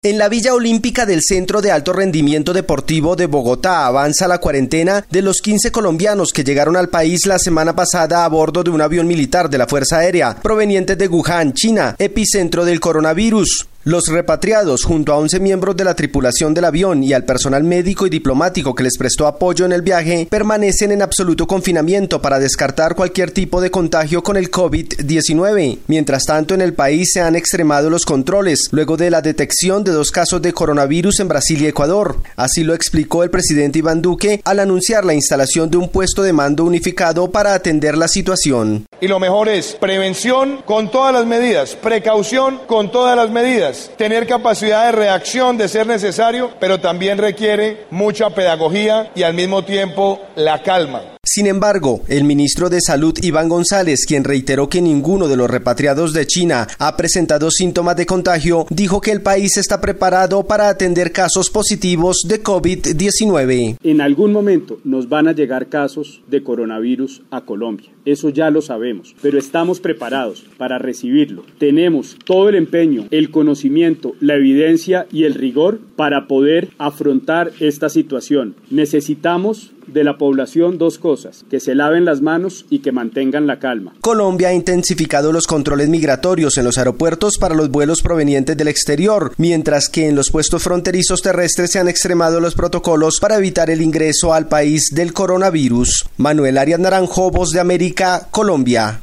VOA: Informe de Colombia